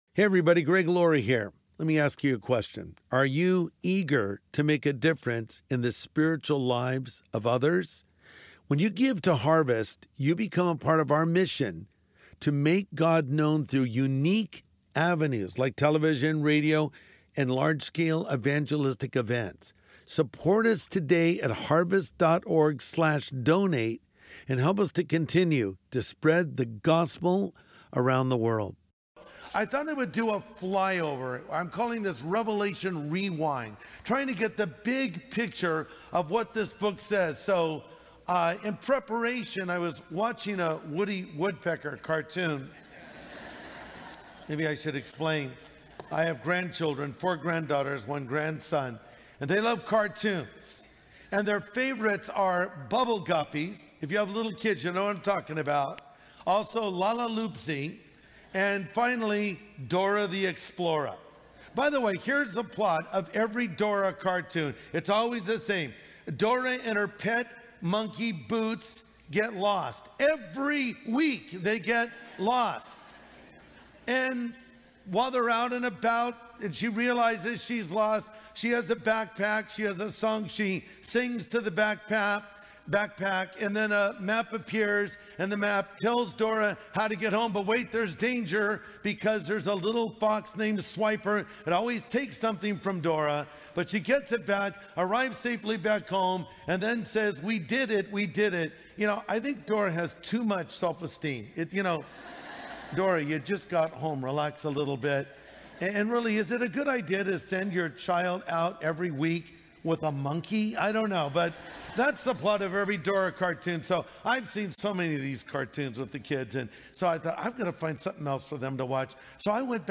In this message, Pastor Greg Laurie gives a flyover of the Book of Revelation.